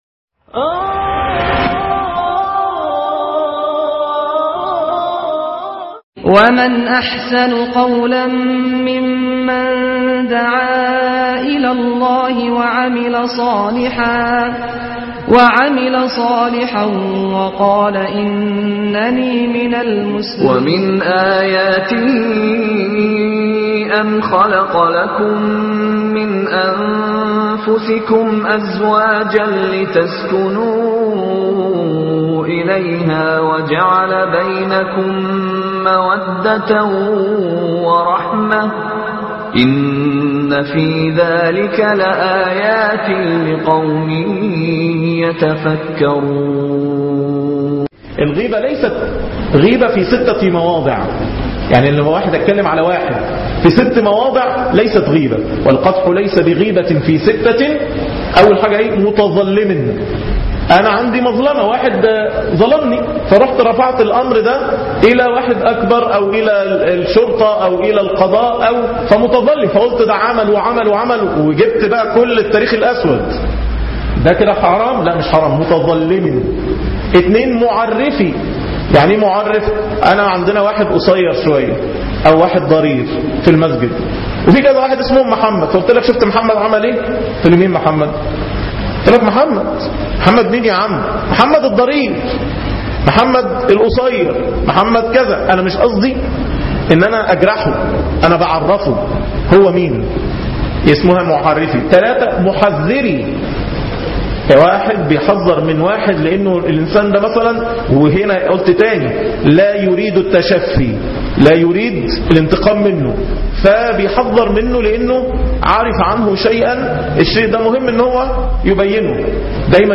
عنوان المادة الدرس الثالث- دورة فقة الزواج من كتاب الفقه الميسر